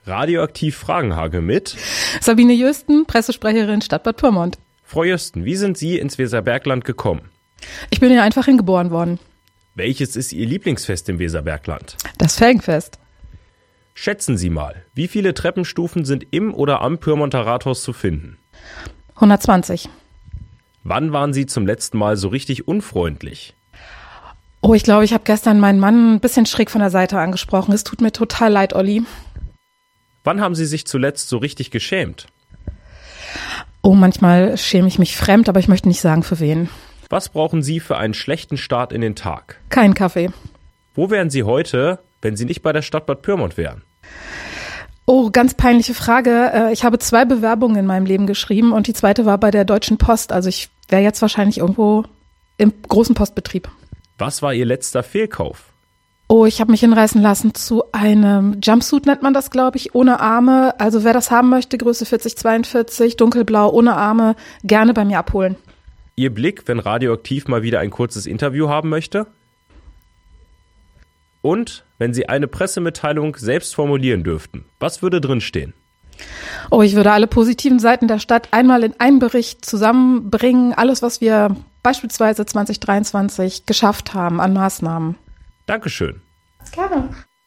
1:28 In den vergangenen Wochen haben wir die „Promis“ aus dem Weserbergland zum Interview gebeten.